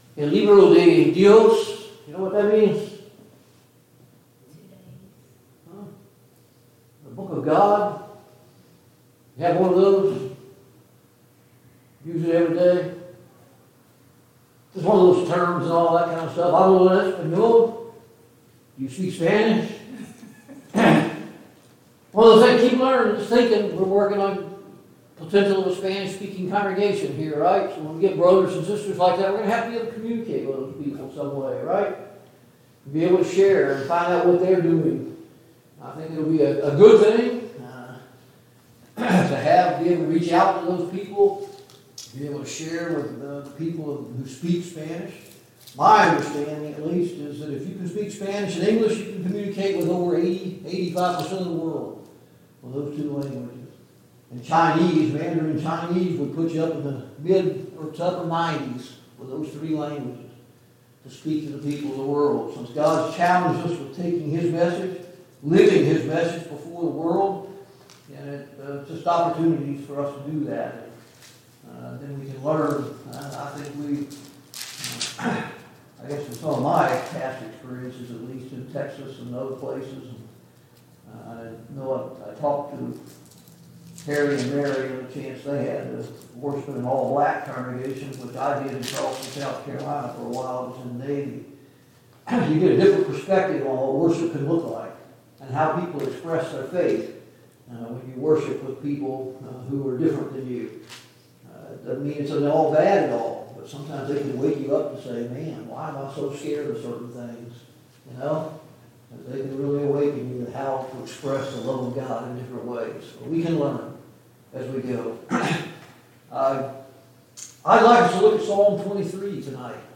Mid-Week Bible Study « Confronting the Lies the World tells Us! 11.